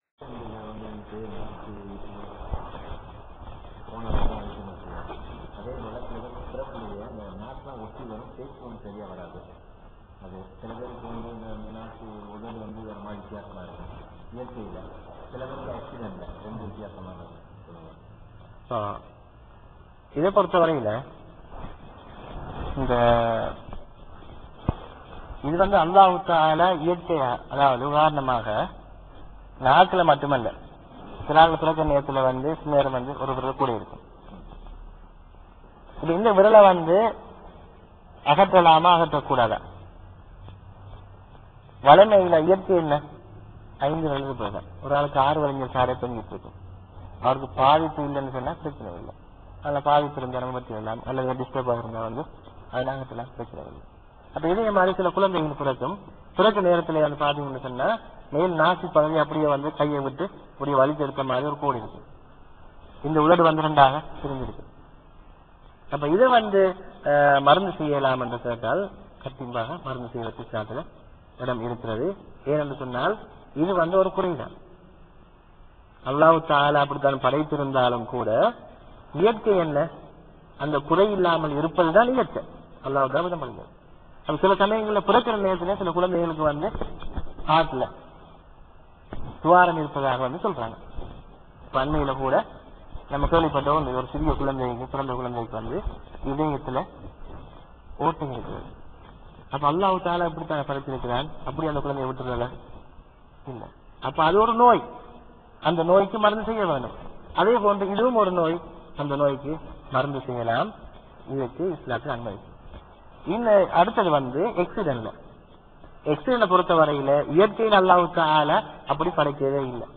வாராந்திர பயான் நிகழ்ச்சி (கேள்வி-பதில் பகுதி)
இடம் : அல்-கப்ஜி, சவூதி அரேபியா